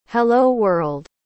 I heard the voice of a digital angel. She sounded a lot more natural than any of the previous versions I've had.
google_speech "Hello world"